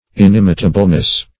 -- In*im"i*ta*ble*ness, n. -- In*im"i*ta*bly, adv.